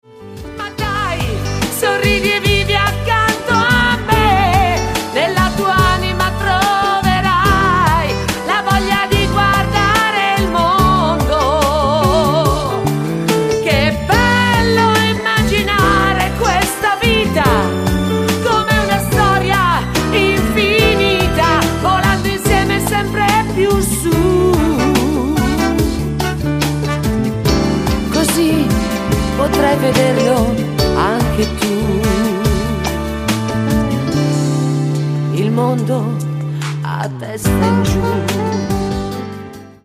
MODERATO SLOW  (3.33)